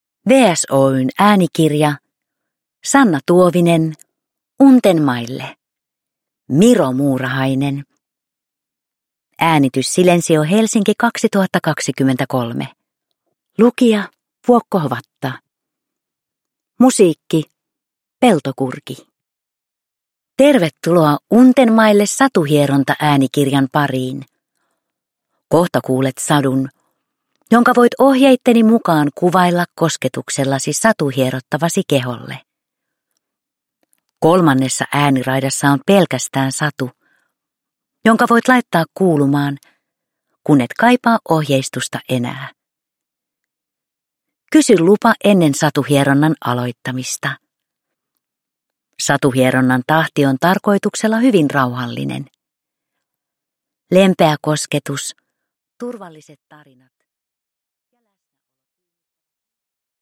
Untenmaille – Miro-muurahainen – Ljudbok – Laddas ner
Untenmaille äänikirjat sisältävät teoksia varten sävelletyn rauhoittavan ja elämyksellisen musiikillisen äänimaiseman.